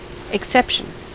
Ääntäminen
US : IPA : [ɪkˈsɛp.ʃən]